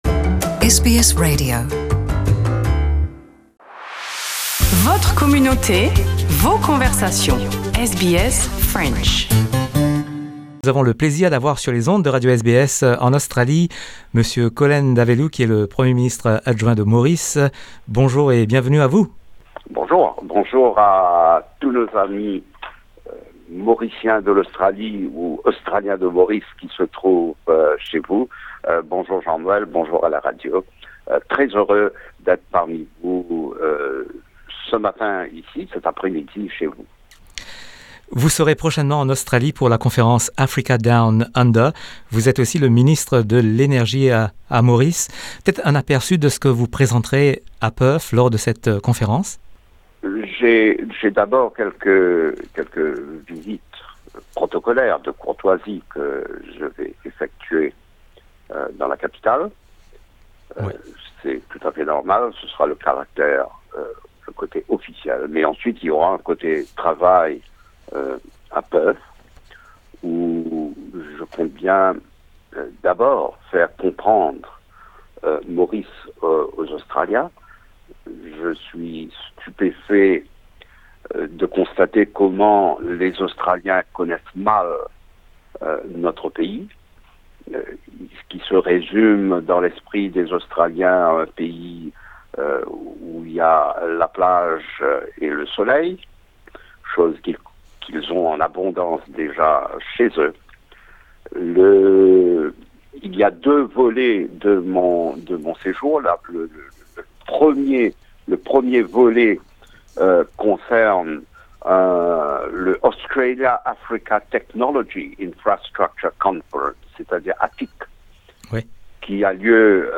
Nous sommes en ligne avec Ivan Collendavelloo, le Deputy Prime Minister de Maurice qui arrive prochainement en Australie pour un voyage officiel. Il est aussi le ministre de l’énergie et il sera présent à la conférence ‘Africa Down Under’ qui se déroulera à Perth du 27 au 31 août.